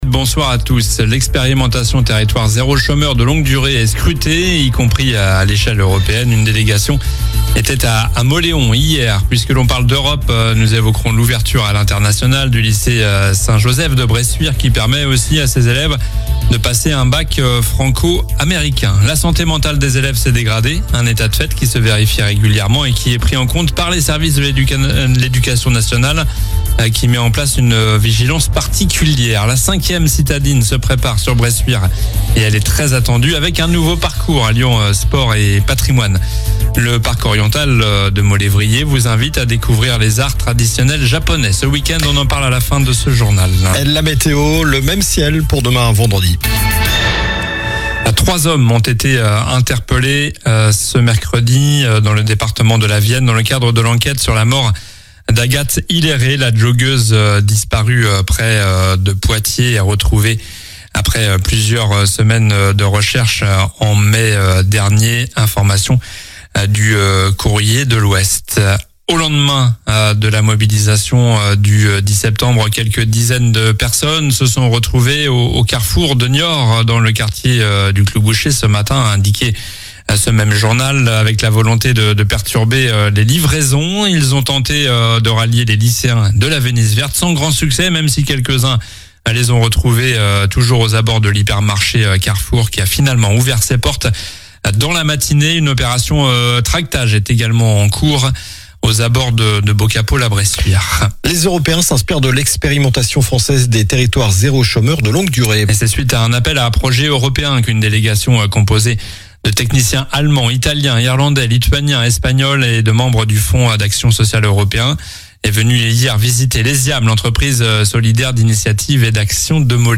Journal du jeudi 11 septembre (soir)